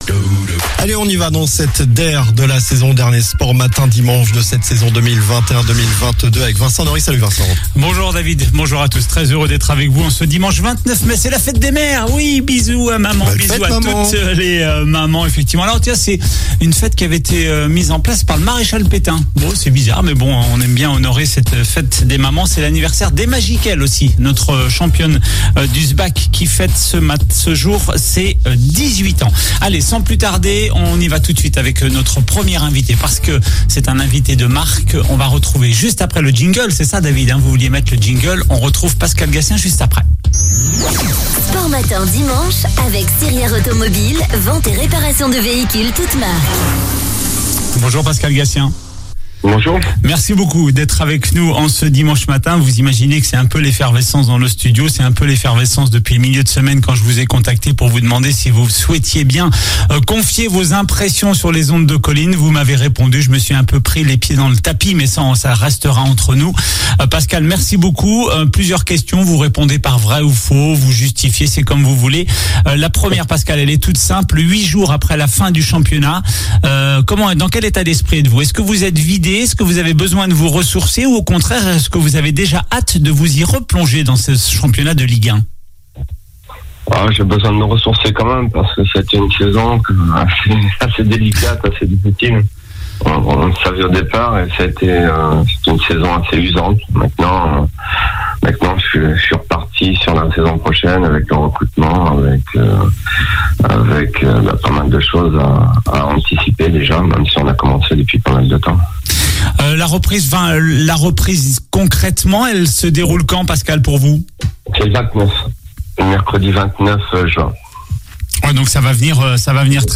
sports infos